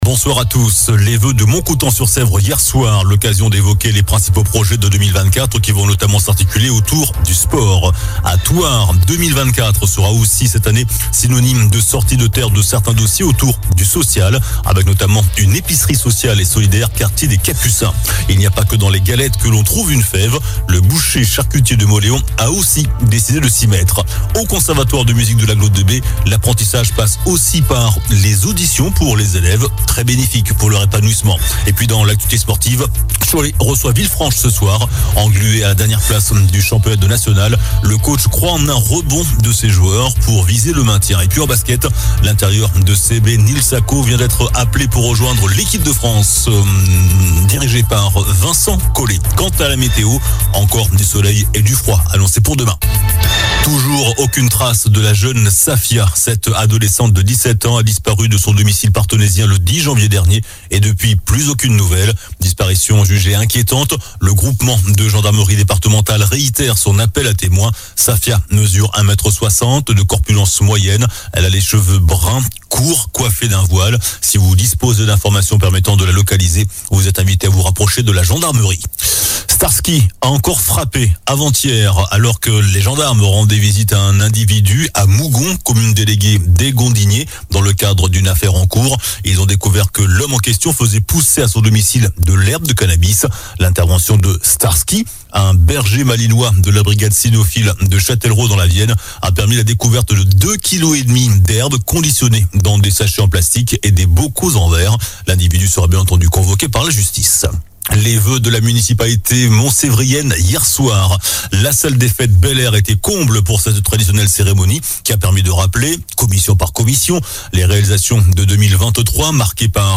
Journal du vendredi 19 janvier (soir)